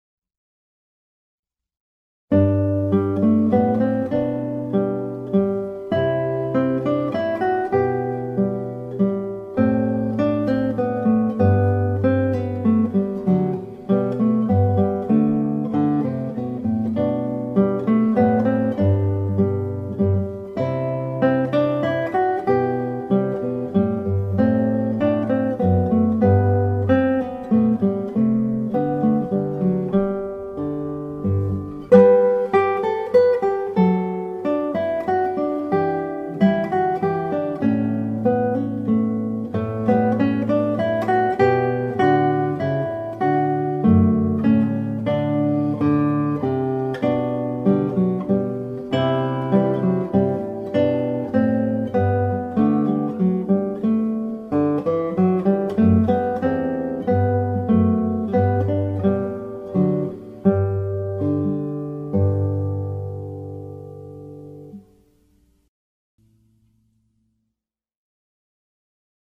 Na pausa do almoço, num “break” à tarde, após um longo dia de trabalho, ou pela noite fora…  sabe sempre bem ouvir uma música relaxante, este Minueto encarrega-se bem disso.
Interpretada aqui na guitarra clássica com um andamento lento.
Minueto ou minuet é uma dança em compasso de 3/4, de origem francesa, ou uma composição musical que integra suítes e sinfonias.